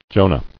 [Jo·nah]